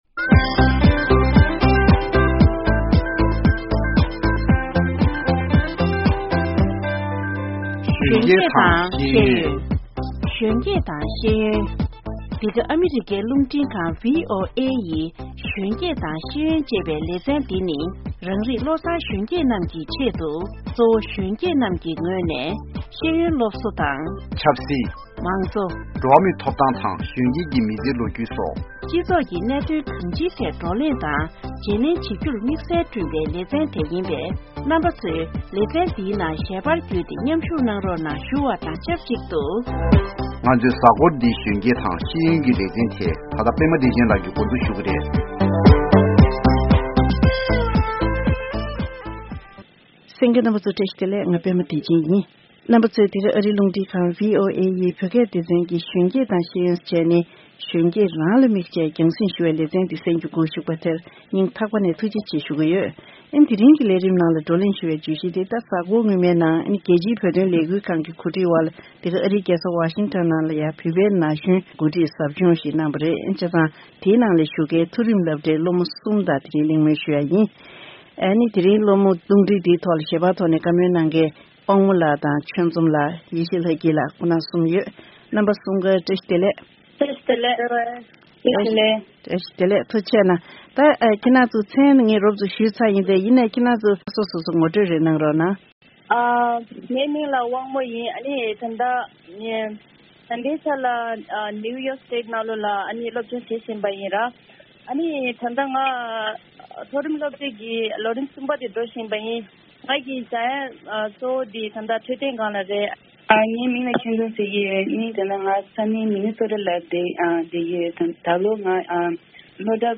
ཟབ་སྦྱོང་དེར་མཉམ་ཞུགས་བྱེད་མཁན་བོད་པའི་སློབ་ཕྲུག་གསུམ་ལ་བཅར་འདྲི་ཞུས་པ་དེ་གསན་རོགས་གནང་།།